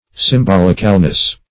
Sym*bol"ic*al*ness, n.